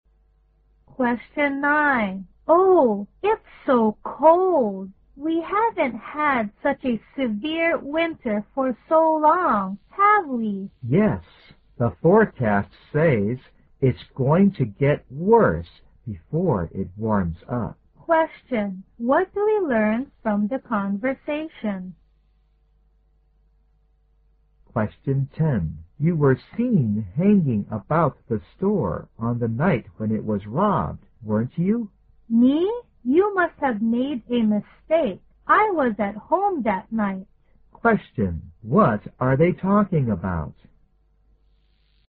在线英语听力室133的听力文件下载,英语四级听力-短对话-在线英语听力室